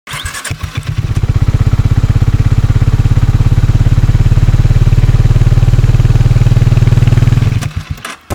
bike-sound-start-stop.mp3